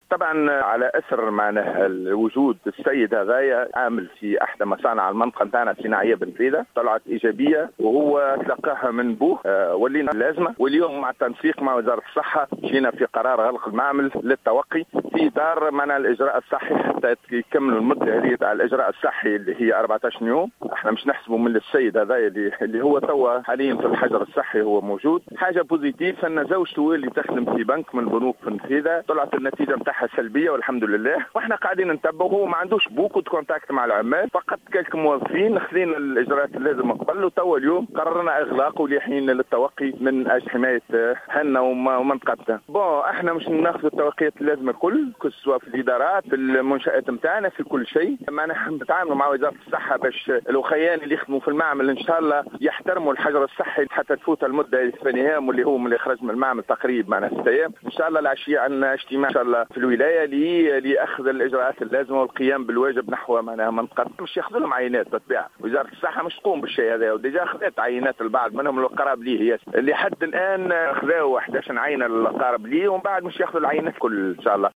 أكد رئيس بلديّة النفيضة عبد اللطيف حمودة، في تصريح لـ "الجوهرة أف أم" اليوم الأحد أنه بالتنسيق مع وزارة الصحة تم اتخاذ قرار غلق مصنع بالنفيضة اثر تسجيل اصابة بكورونا لدى أحد الموظفين علما وأن العدوى انتقلت اليه من والده في سيدي بوعلي والقادم من فرنسا.